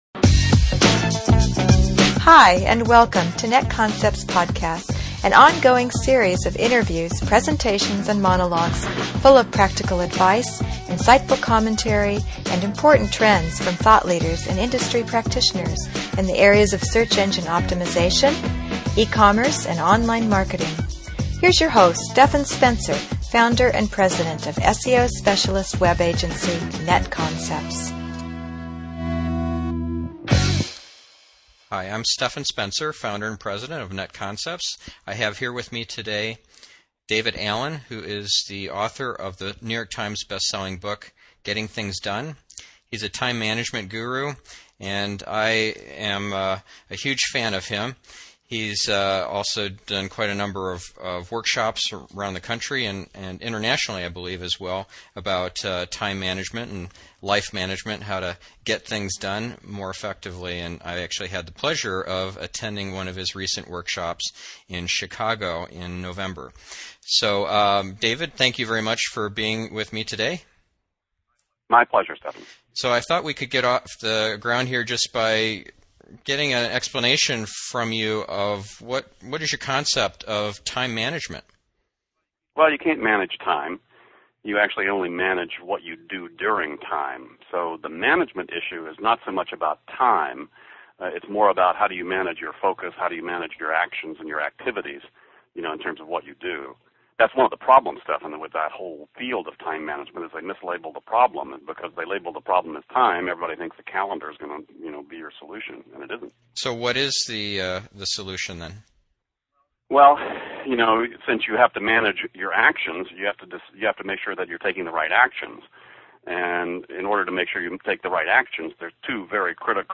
david-allen-interview.mp3